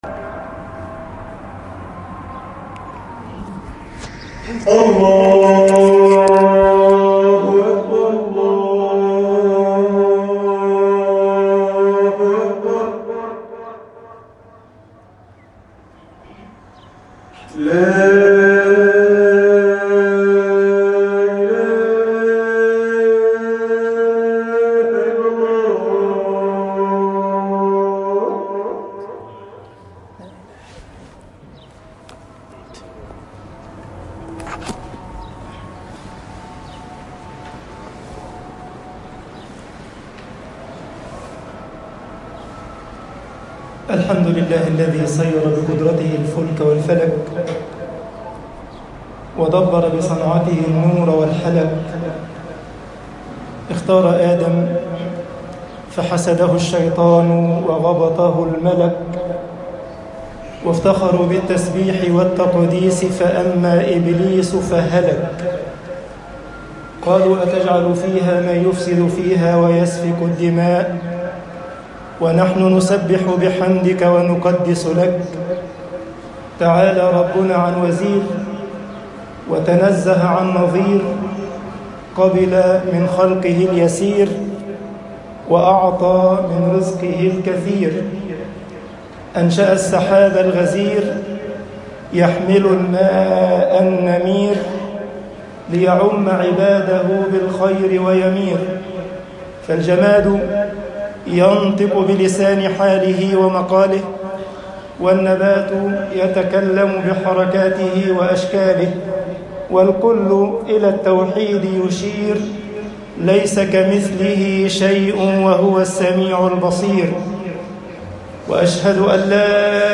خطب الجمعة - مصر جيل لن يتكرّر طباعة البريد الإلكتروني التفاصيل كتب بواسطة